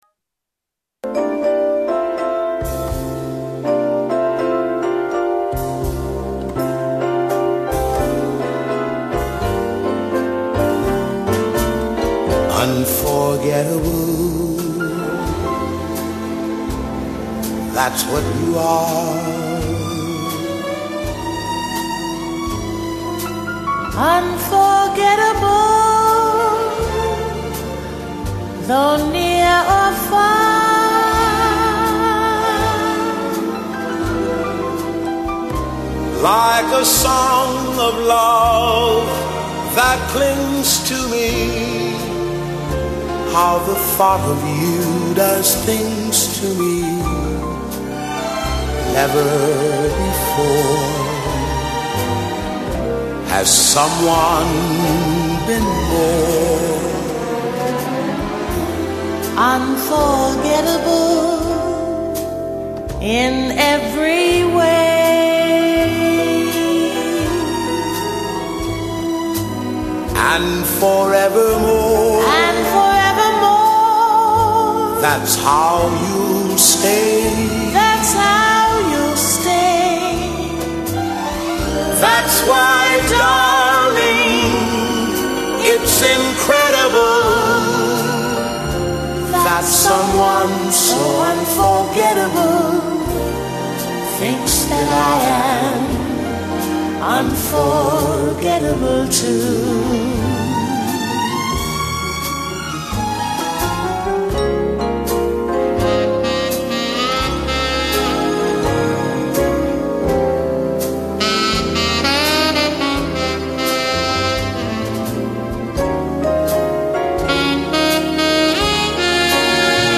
دوئت